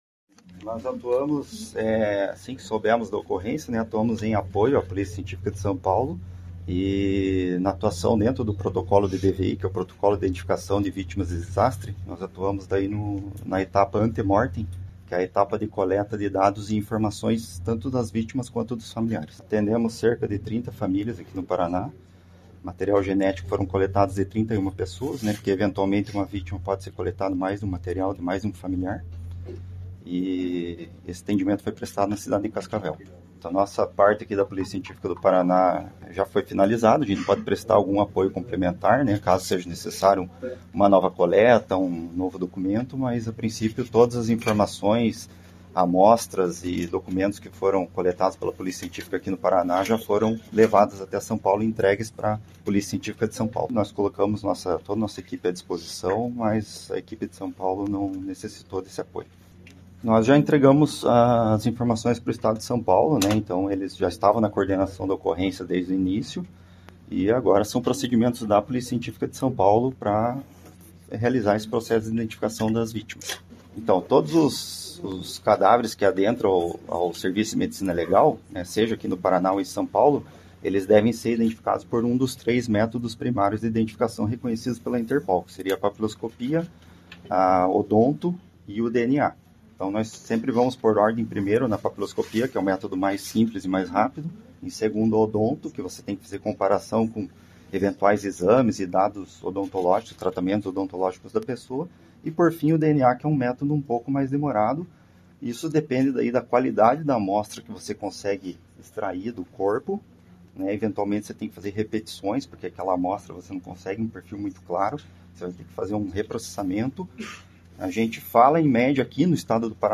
Sonora do diretor Operacional da Polícia Científica, Ciro Pimenta, sobre a conclusão dos trabalhos da Polícia Científica do Paraná no acidente aéreo de Vinhedo